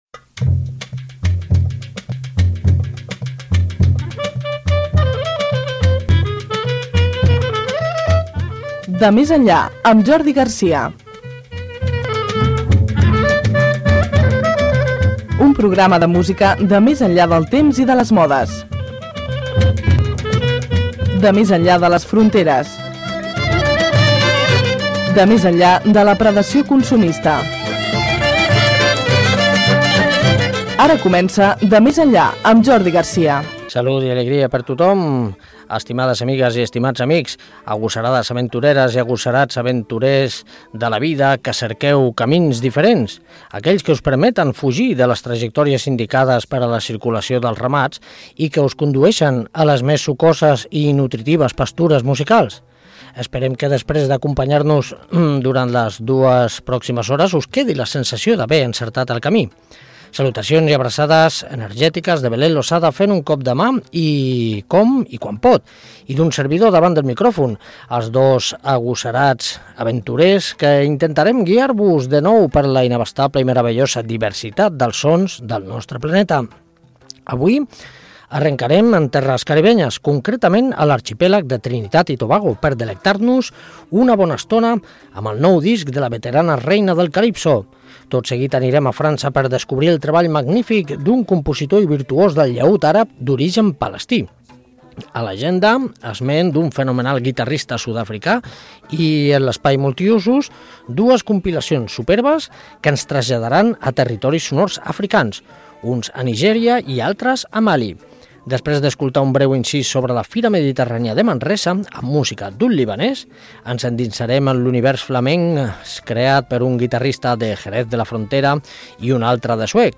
Careta, presentació del programa sobre músiques diverses de tot el planeta, sumari de continguts i tema musical
Musical
FM